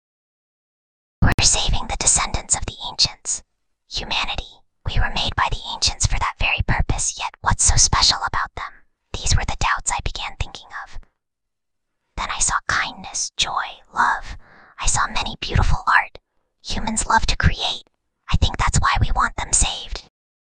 Whispering_Girl_22.mp3